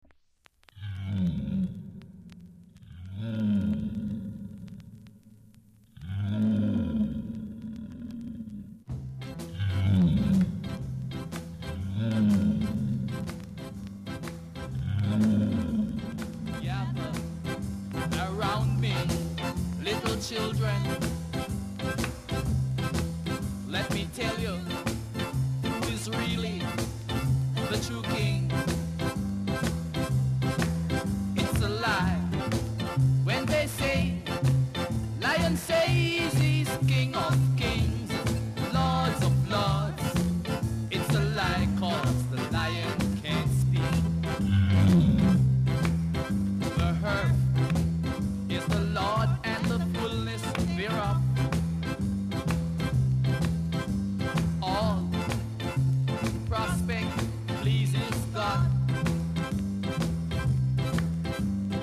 ※小さなチリノイズが少しあります。盤は薄い擦り傷が少しあります。